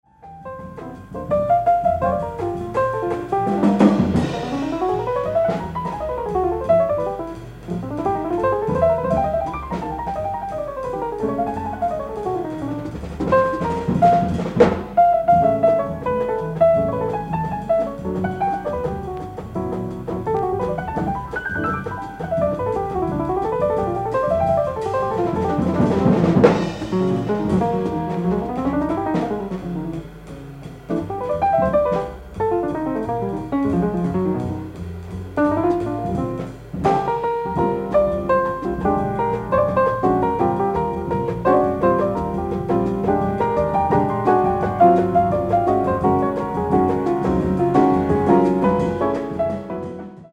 ライブ・アット・アンティーブ、フランス
※試聴用に実際より音質を落としています。